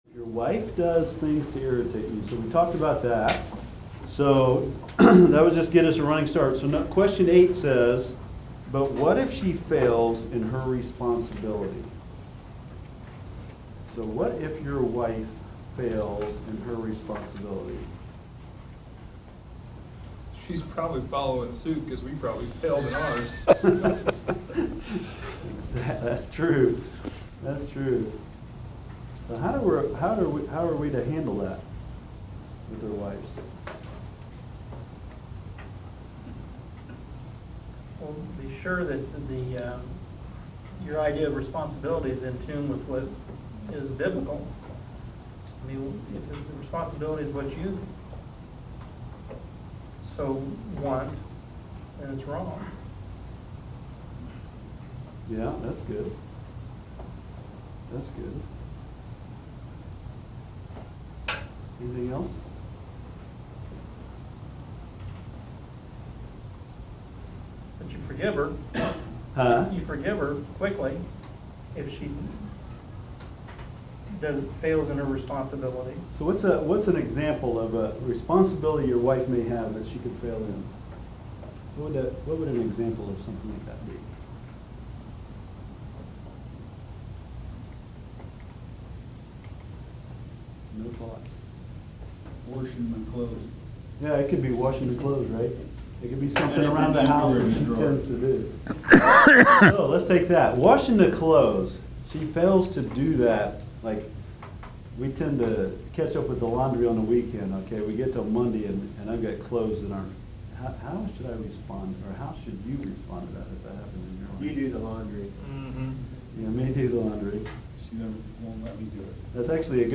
This is class #6 (of 6) "Loving Your Wife" teaches you how to analyze and critique how your wife sees
"Loving Your Wife" teaches you how to analyze and critique how your wife sees your love toward her being demonstrated. Helpful discussions, interaction with the teacher/class, and clear instruction from the Word of God challenges you to understand your wife.